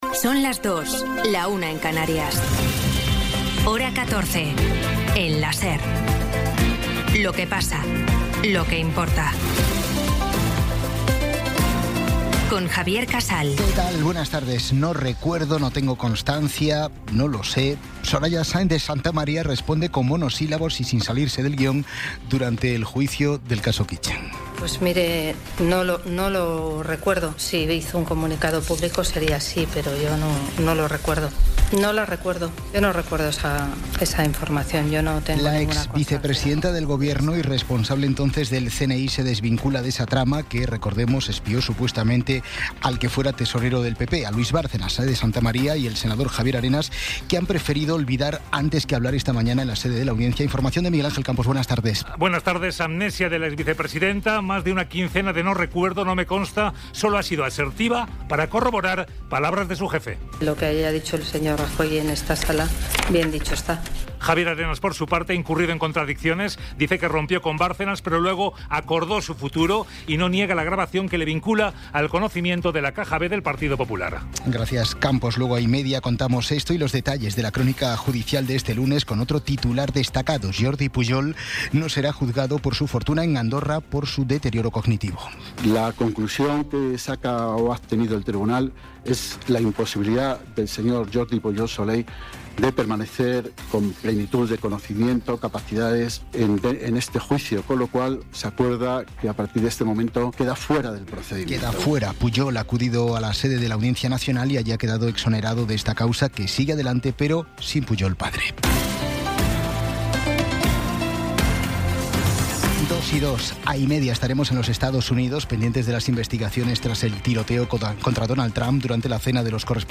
Resumen informativo con las noticias más destacadas del 27 de abril de 2026 a las dos de la tarde.